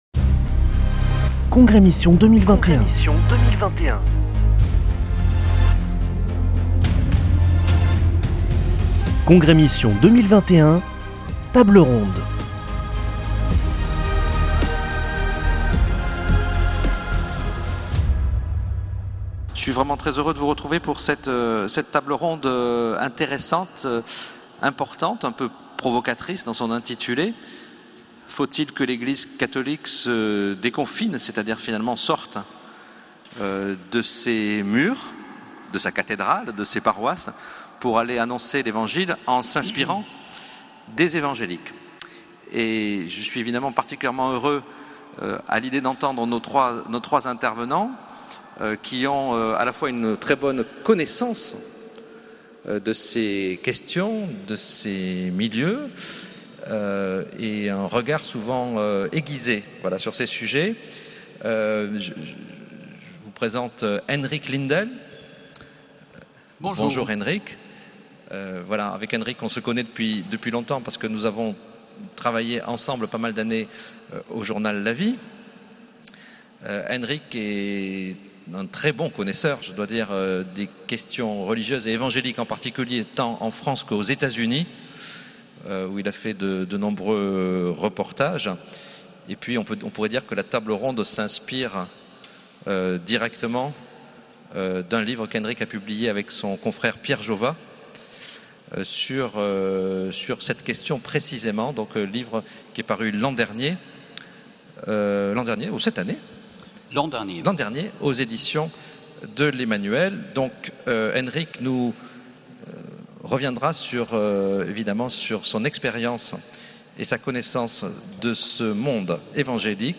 Congrès Mission du 1er au 3 octobre à Toulouse - Table ronde 6